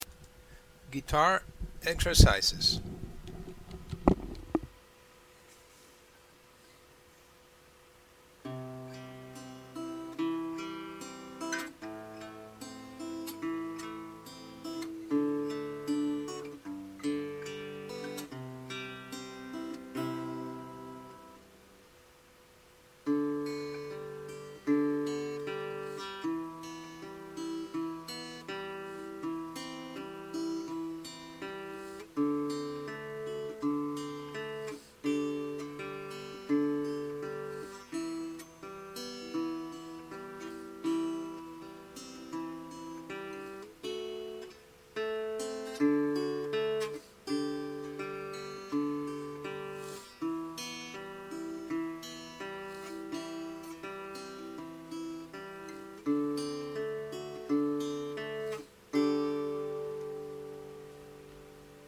guitar beginner exercise 1